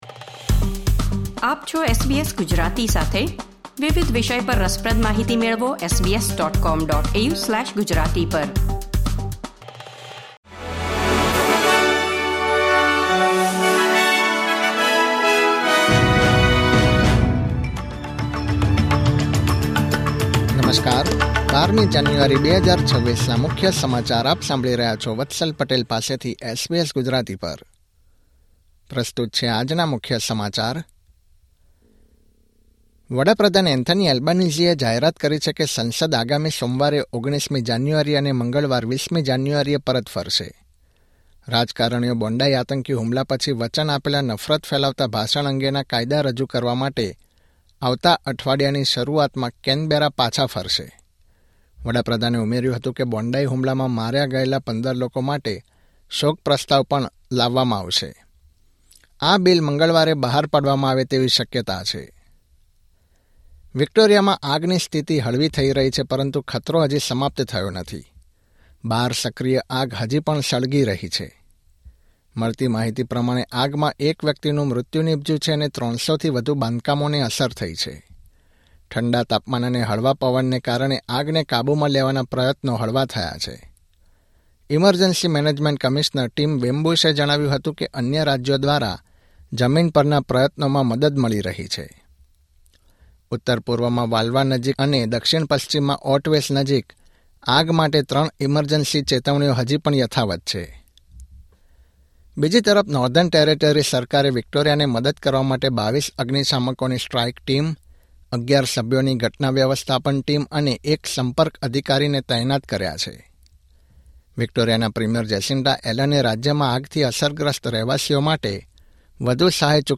Listen to the latest Australian news